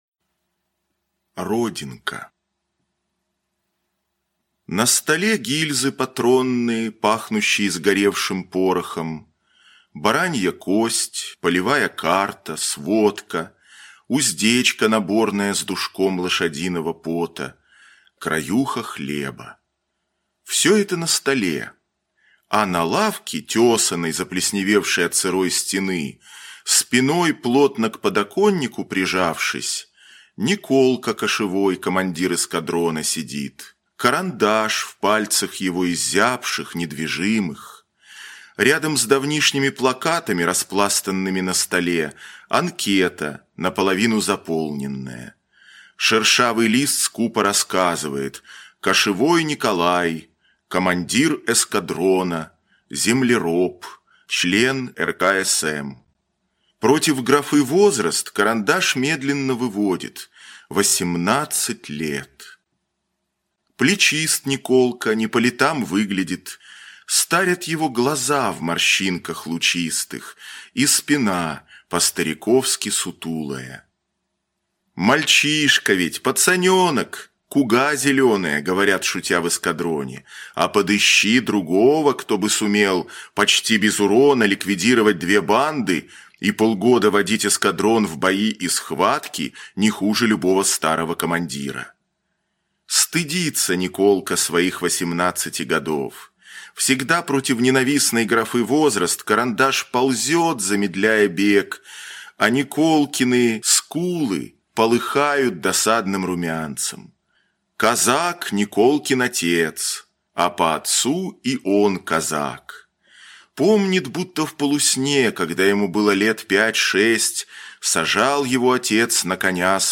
Родинка - аудио рассказ Шолохова - слушать онлайн